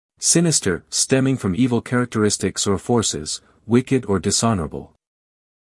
英音/ ˈsɪnɪstə(r) / 美音/ ˈsɪnɪstər /